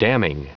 Prononciation du mot damning en anglais (fichier audio)
Prononciation du mot : damning